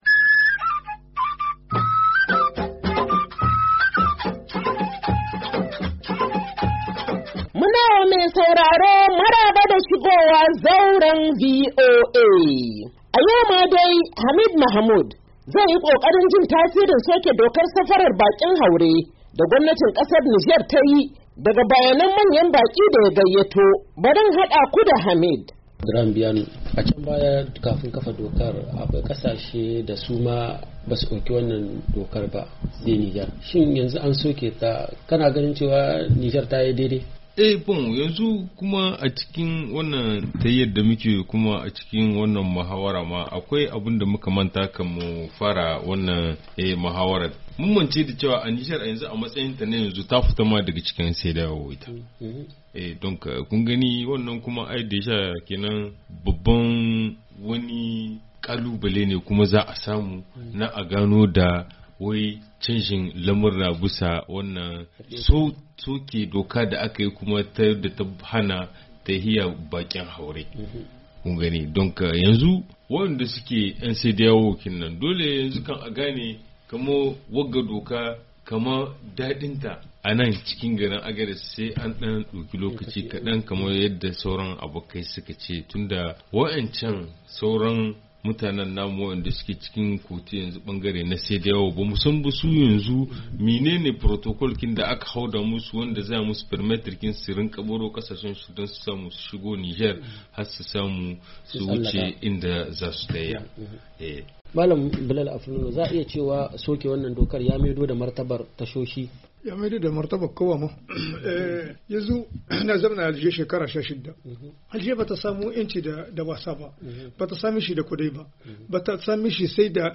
Shirin Zauran VOA na wannan mako, zai kawo muku kashi na karshe na tattaunawa da bakin Zaura a jihar Agadez da ke jamhuriyar Nijar, kan soke dokar haramta safarar bakin haure da aka yi a kasar, bayan ta yi shekaru 8 tana aiwatar da dokar.